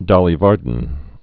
(dŏlē värdn)